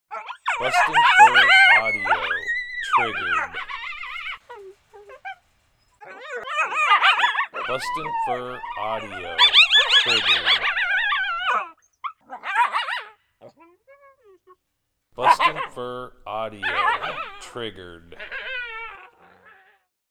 Juvenile coyotes fighting over food. Lots of growl, squalls and yips in this sound.
• Product Code: pups and fights